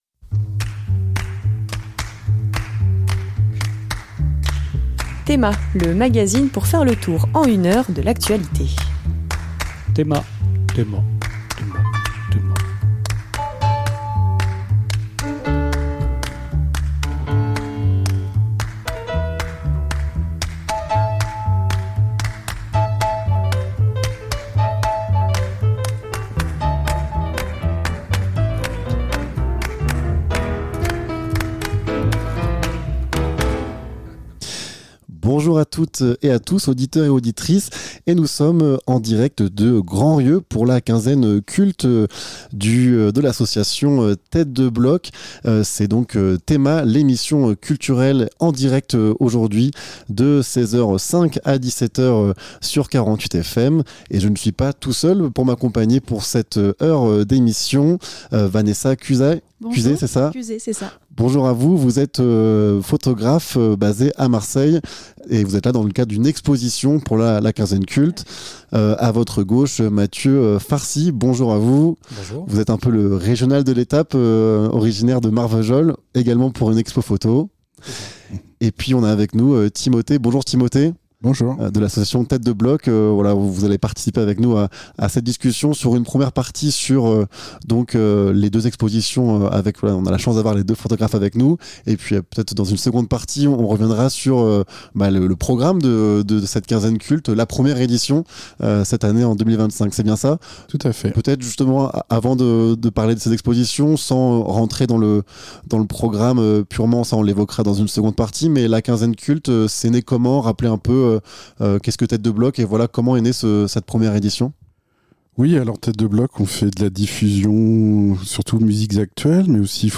Emission du mardi 15 juillet 2025 en direct du Variété, lieu-dit La Bataille (Grandrieu)